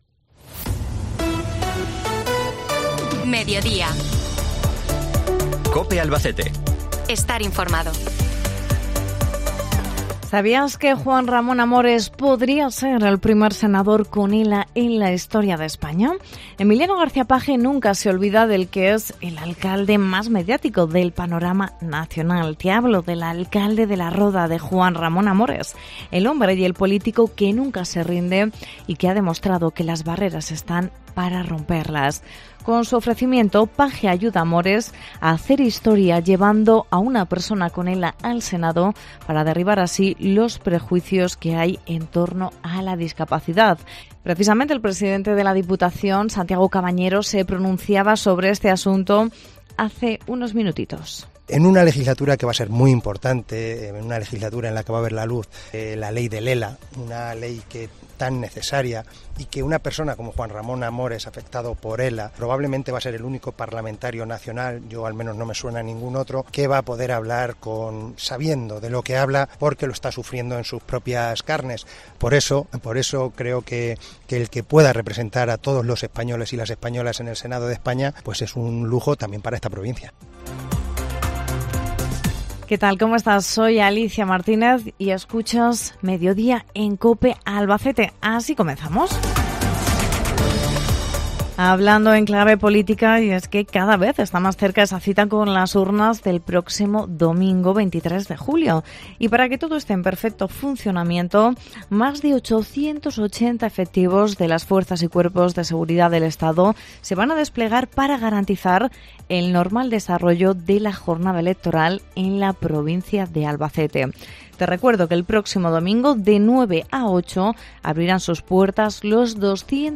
salimos a la calle a realizar una encuesta 'test del manchego'